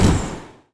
etfx_explosion_mystic02.wav